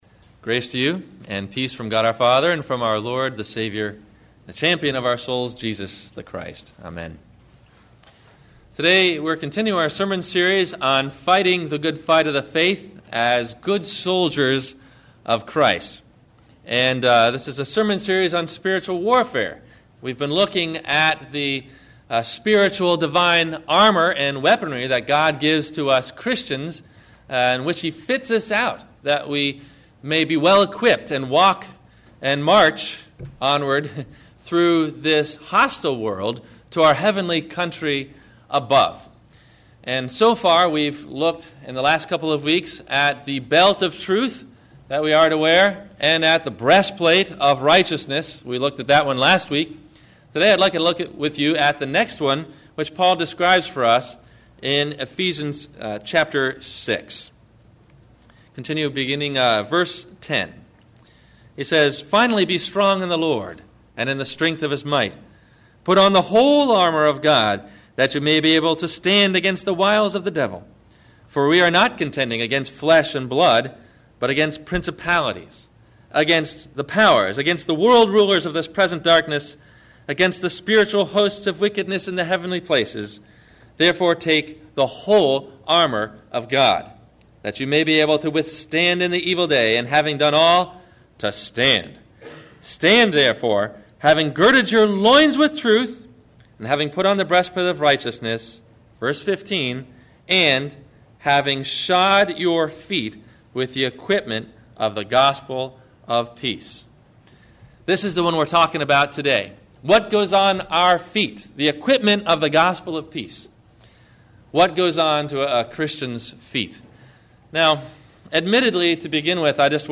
The Shoes of a Christian Soldier – Sermon – October 05 2008